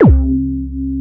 PROBASSHC2-R.wav